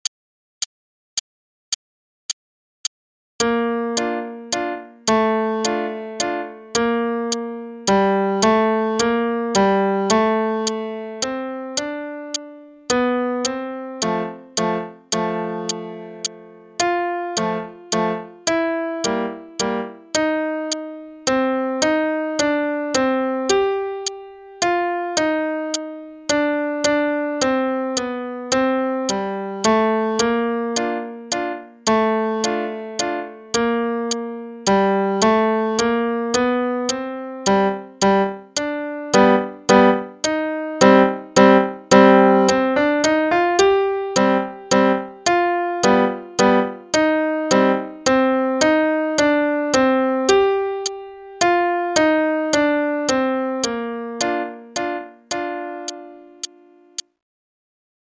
Adult Book 1 (All-in-One): page 110 Sleeping Beauty Waltz (student part, qn=108)